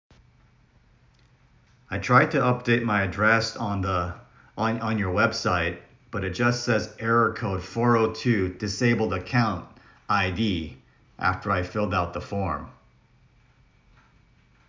An utterance is a short, uninterrupted stretch of speech that one speaker produces without any silent pauses.
The reference transcripts are extremely literal, including when the speaker hesitates and restarts in the third utterance (on the on your).